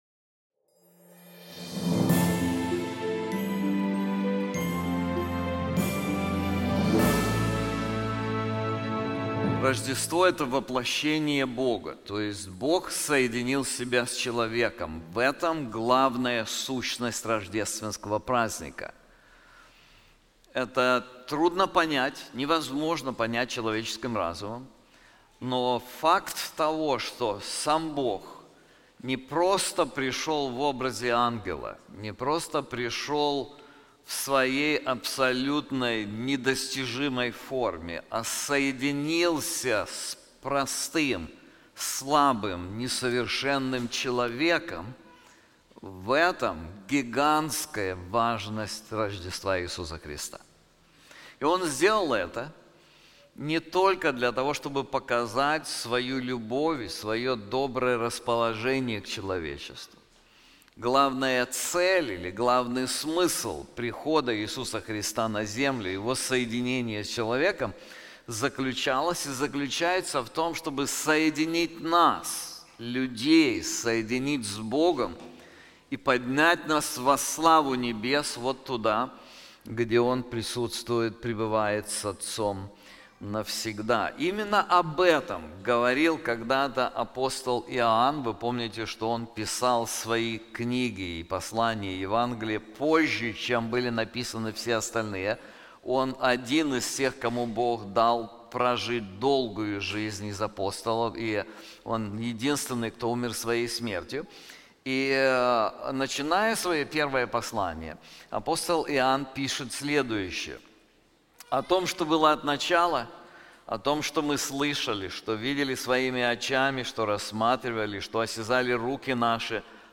This sermon is also available in English:Gospel: The Power for Edifying Relationships • Romans 14:1-15:7